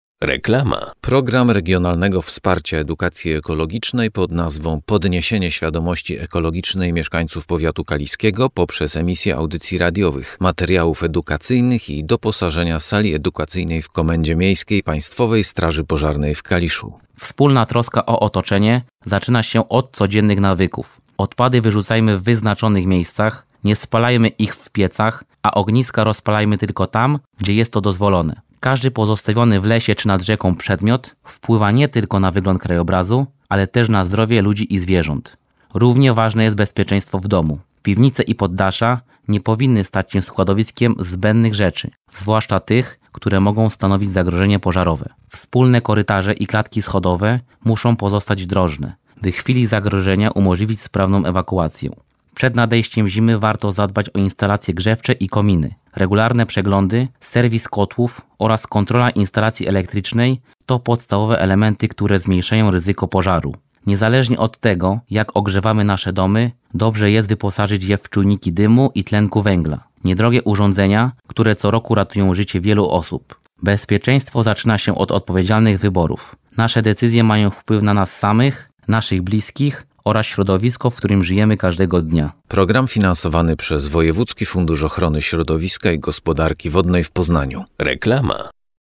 Audycja Radiowa - " Segregacja odpadów "